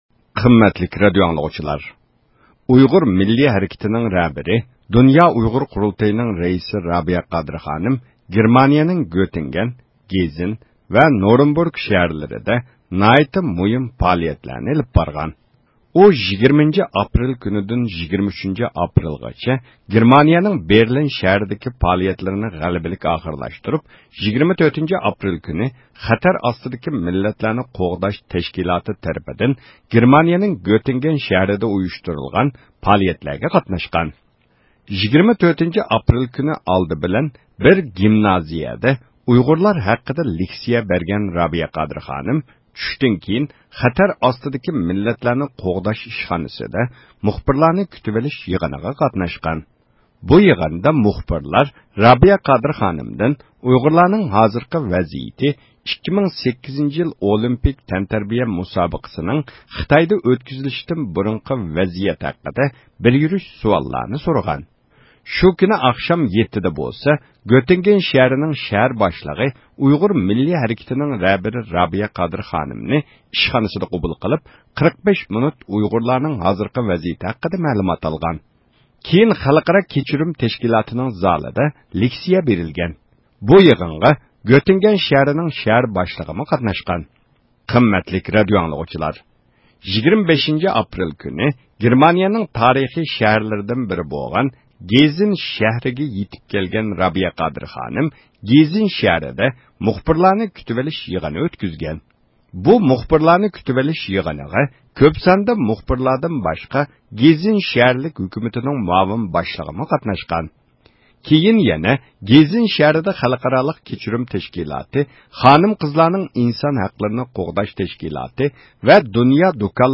تېلېفون زىيارىتى ئېلىپ باردۇق.